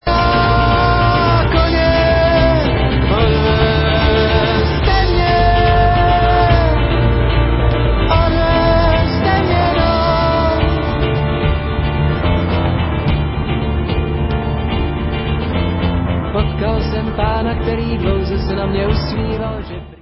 Nahrávalo se ve Zlíně